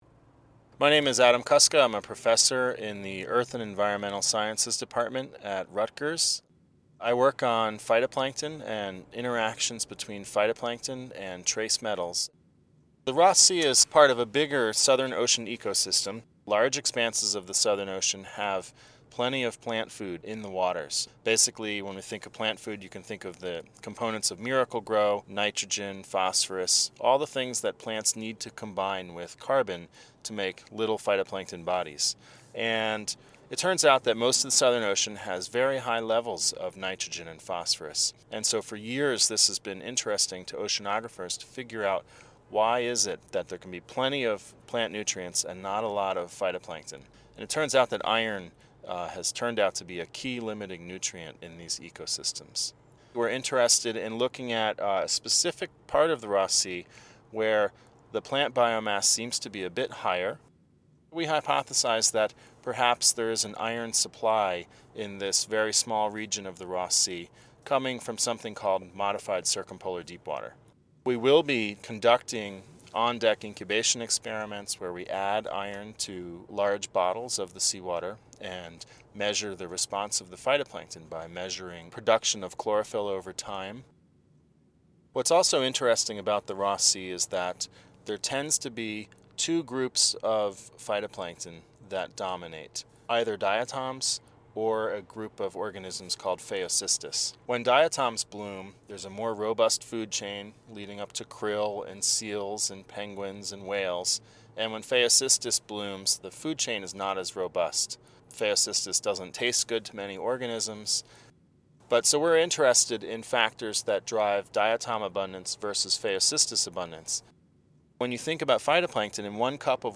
An interview with marine biogeochemist